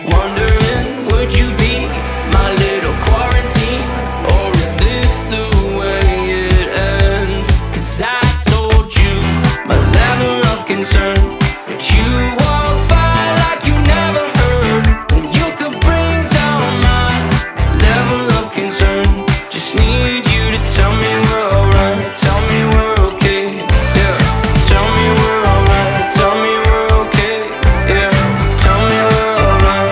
• Alternative
It is a dance-pop, pop rock and dance-rock song